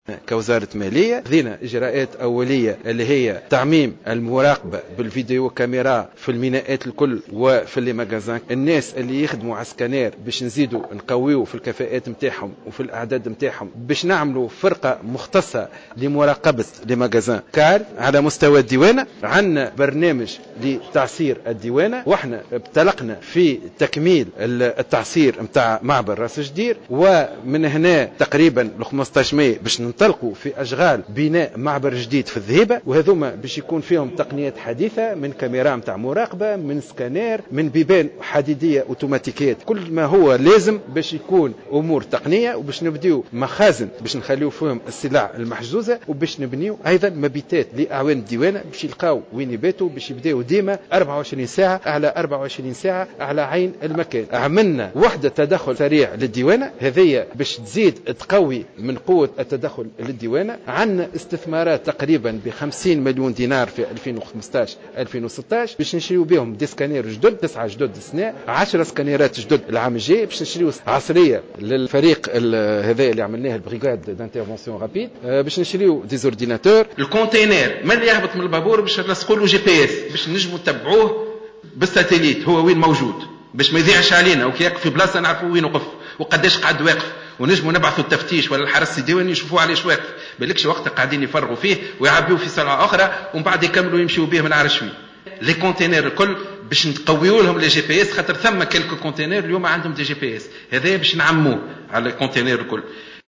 و أوضح شاكر في تصريح لجوهرة أف أم أنه سيتم يوم 15 ماي 2015 الانطلاق في بناء معبر جديد في الذهيبة مجهز بأحدث التقنيات كما سيتم بناء مخازن للسلع المحجوزة و مبيتات لأعوان الديوانة.